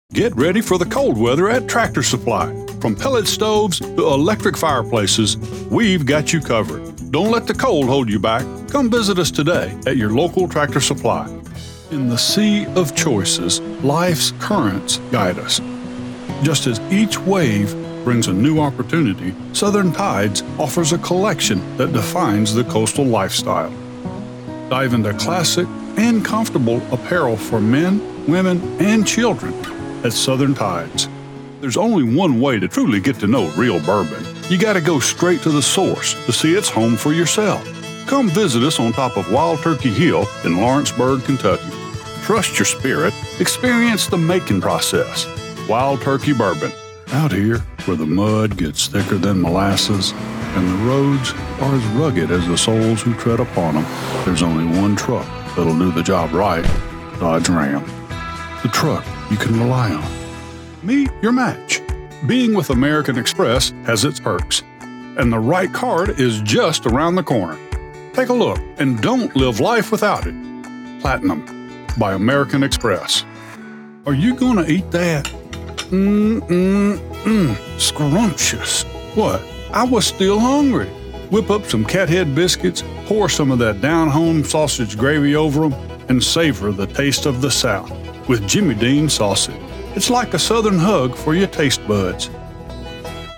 I am a professionally trained voice talent with a mature, slight southern accent.
Commercial Demo
Southern - Mid-south, Appalachian, Coastal, Western (Texas), and Mississippi Delta